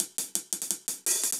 Index of /musicradar/ultimate-hihat-samples/170bpm
UHH_AcoustiHatA_170-01.wav